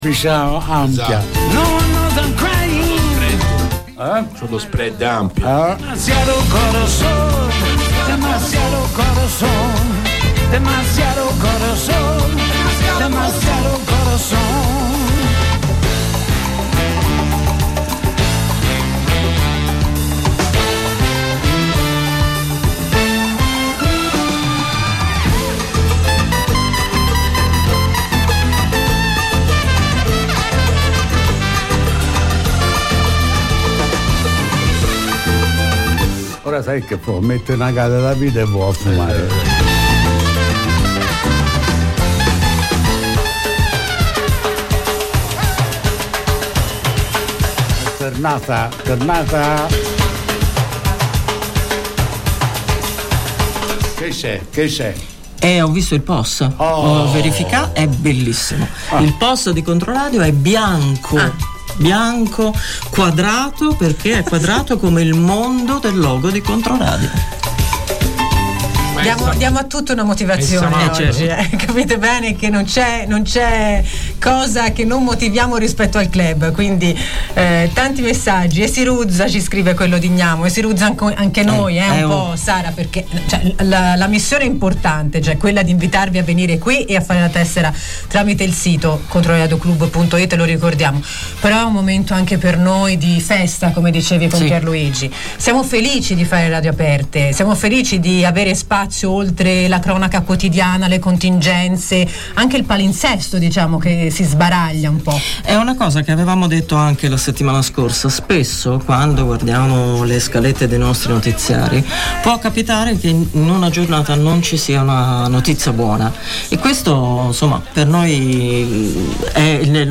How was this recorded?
Vendo compro e scambio in diretta su Controradio